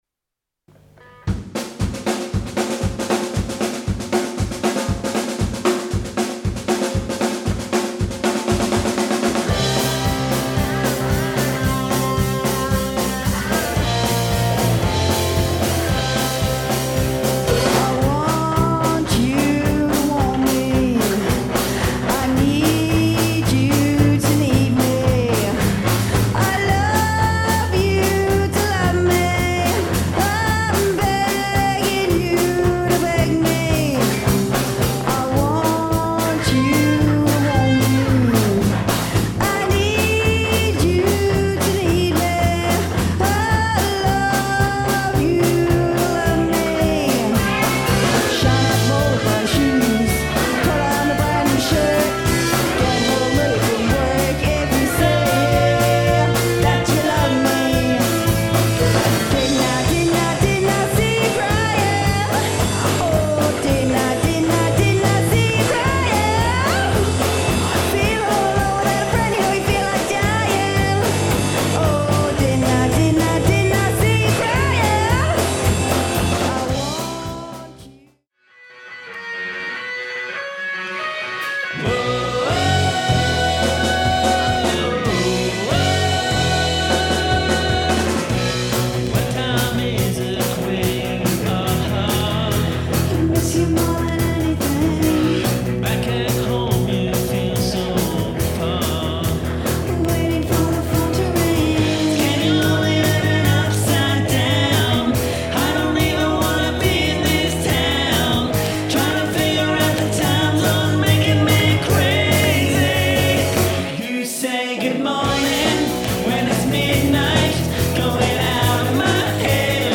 Keyboards
Bass Guitarist